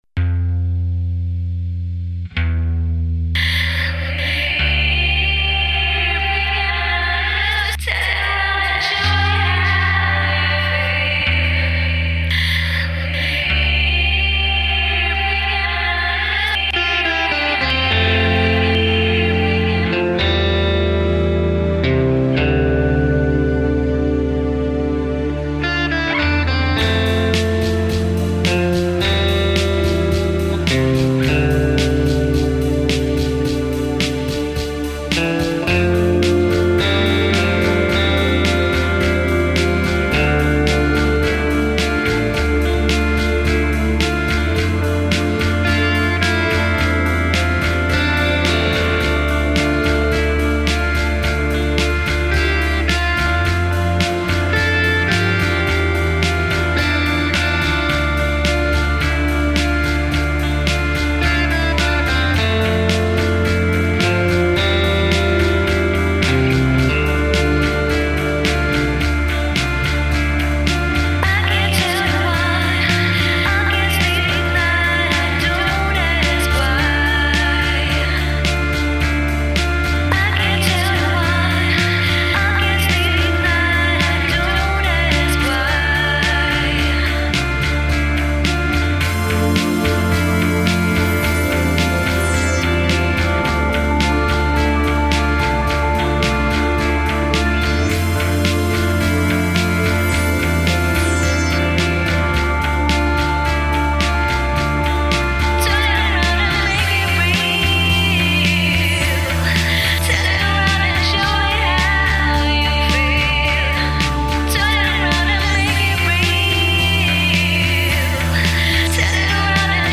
dance/electronic
Breaks & beats
Electro